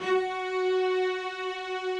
strings_054.wav